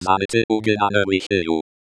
The eSpeak NG is open source software text-to-speech synthesizer. eSpeak NG uses a “formant synthesis” method.
Run the following command to synthesis an audio wav file for the sentence.
The sound is not human, this is just a computerized audio.